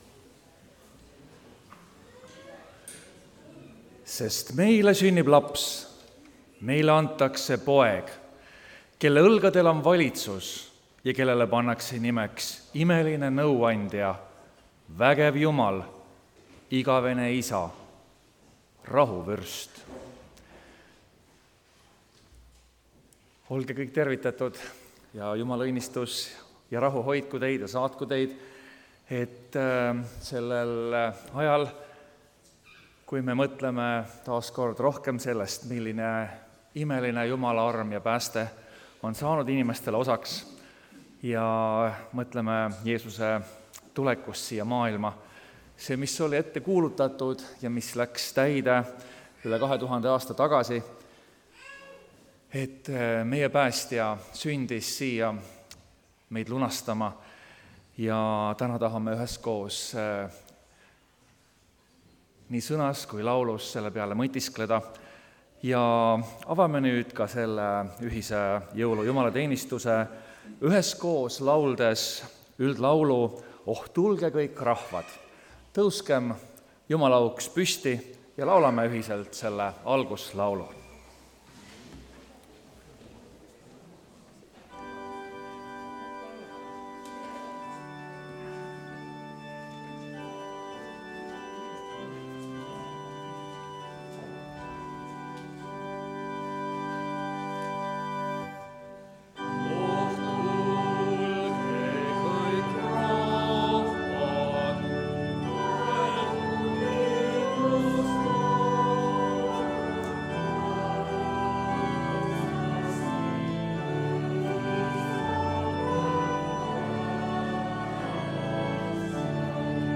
kategooria Audio / Jutlused / Teised
Jõuluõhtu Jumalateenistus (Tallinnas)